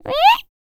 Index of /90_sSampleCDs/Spectrasonics Vocal Planet CD4 - R&B Dance/3 FEM. HUMAN